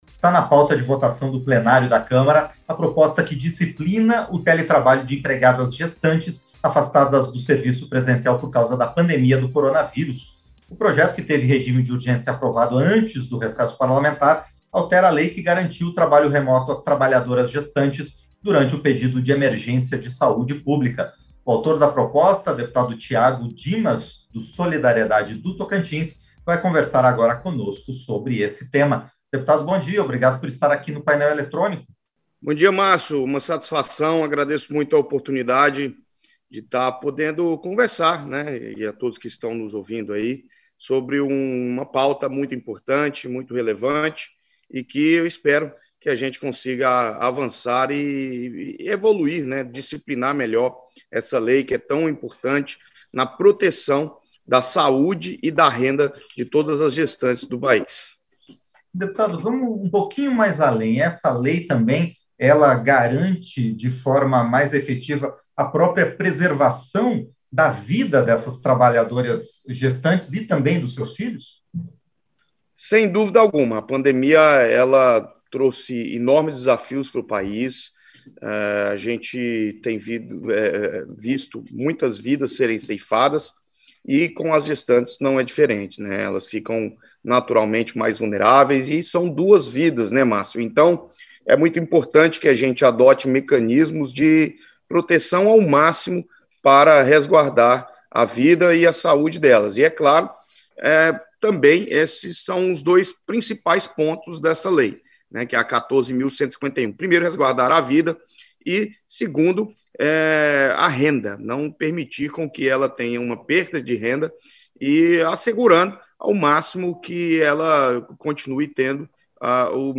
Entrevista - Dep. Tiago Dimas (SD-TO)